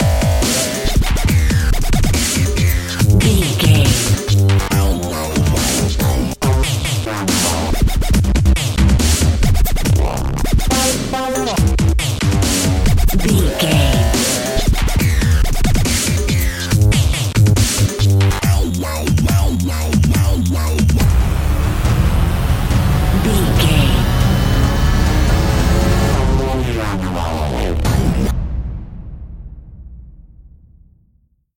Epic / Action
Aeolian/Minor
strings
drums
synthesiser
orchestral hybrid
dubstep
aggressive
energetic
intense
synth effects
wobbles
driving drum beat